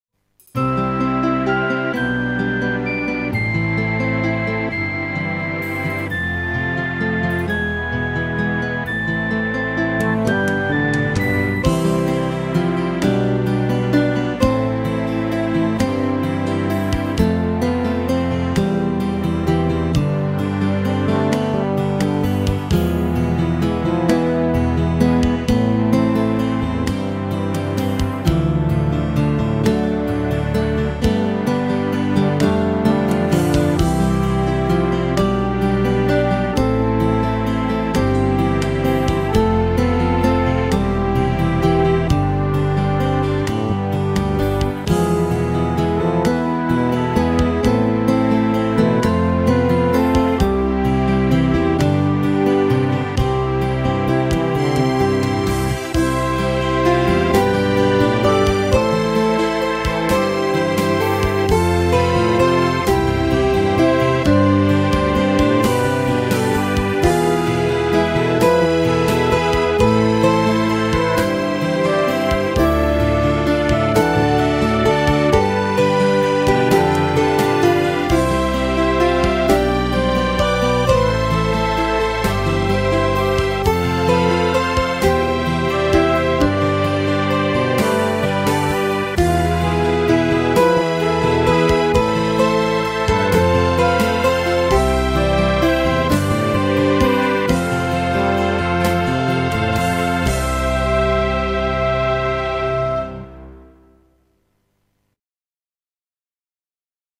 -Queda do Império- (Vitorino) para flauta de bisel e voz.mp3